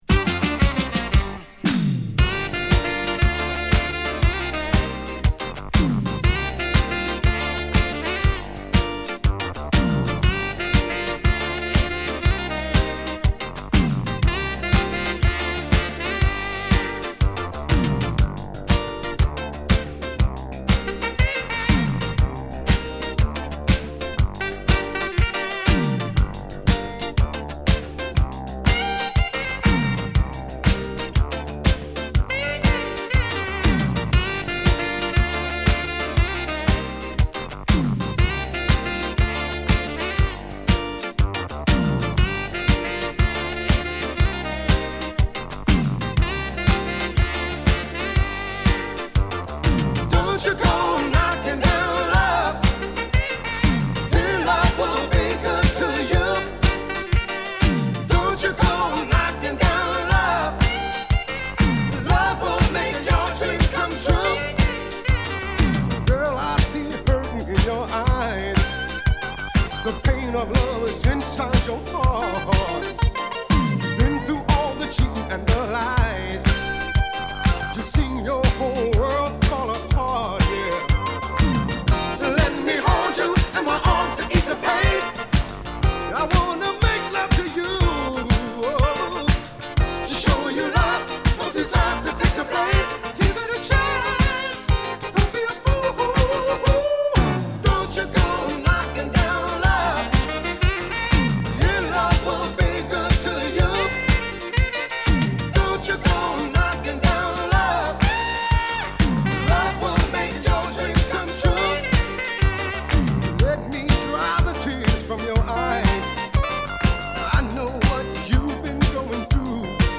アーバンなサックスとアイランド風味のステッパーリズムを軸にソウルフルなヴォーカルを聴かせるディスコチューン！